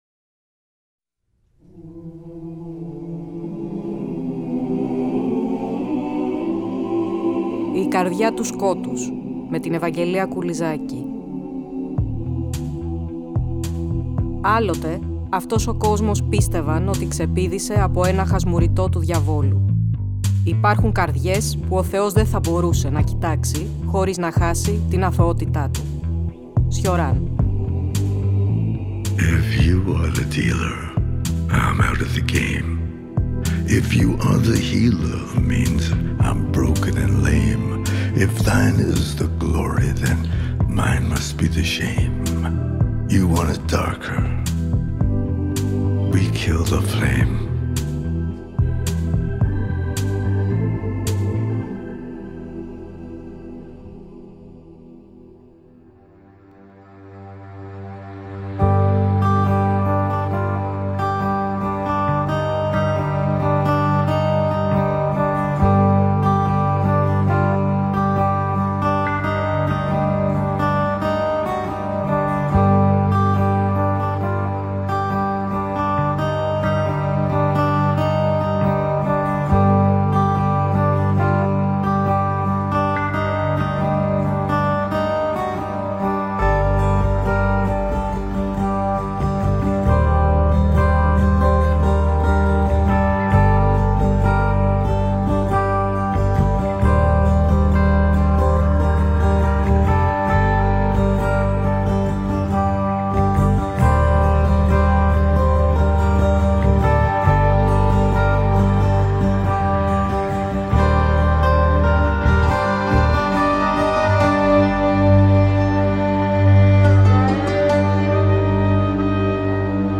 Ακούστε το 4ο επεισόδιο ενός νέου Κύκλου της εκπομπής, που μεταδόθηκε την Κυριακή 15 Μαρτίου από το Τρίτο Πρόγραμμα.